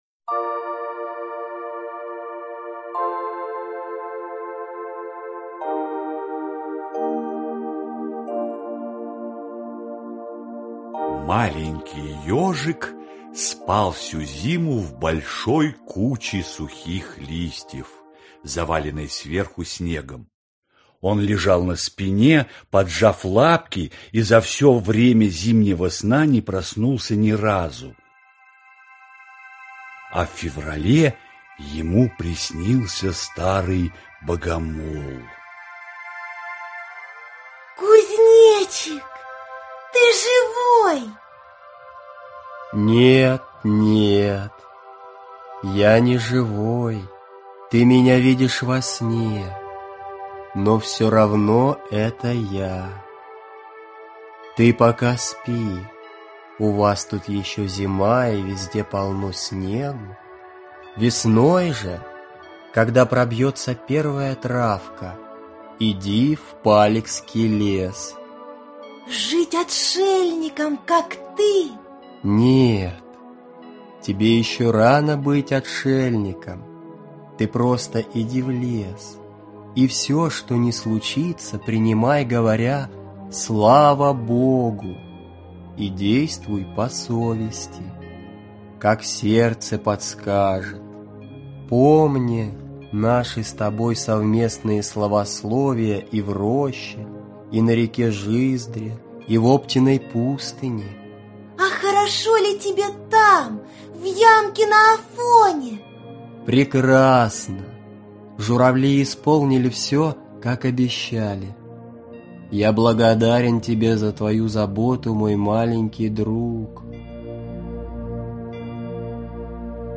Аудиокнига Удивительные истории маленького ежика | Библиотека аудиокниг